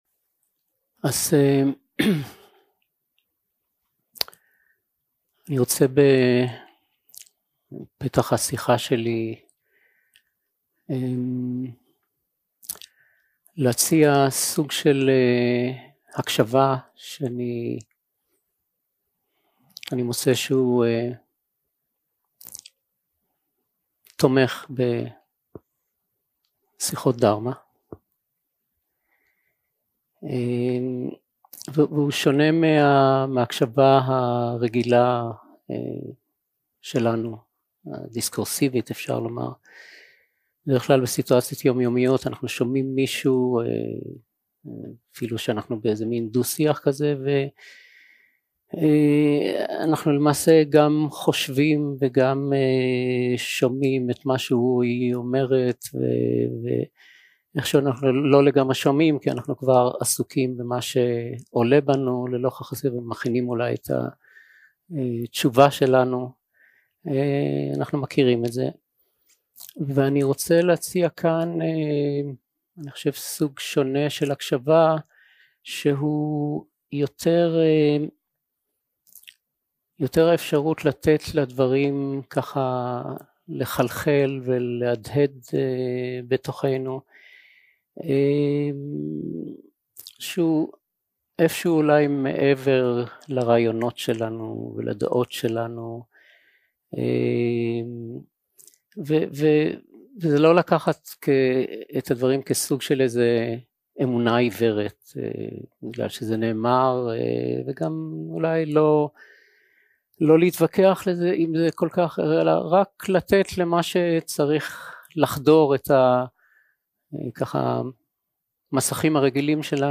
Dharma Talks שפת ההקלטה